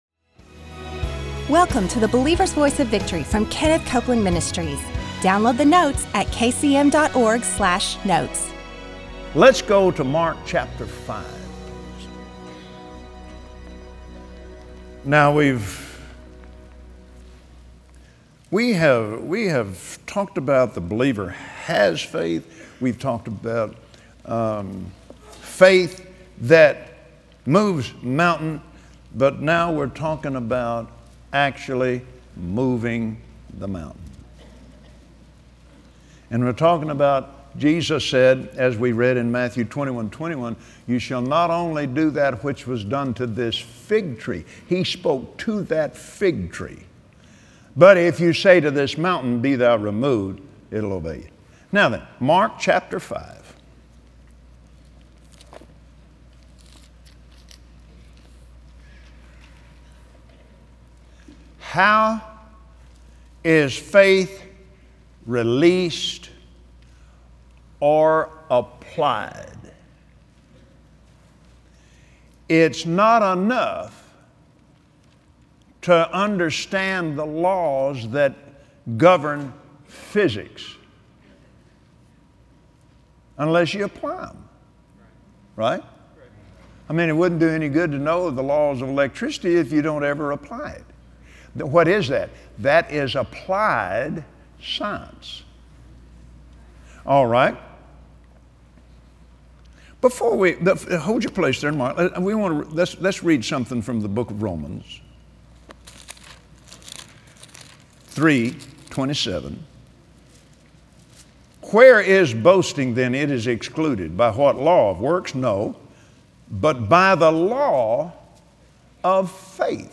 Today Kenneth Copeland summarizes his 3-week Faith study with a teaching on how to move the mountains in your life.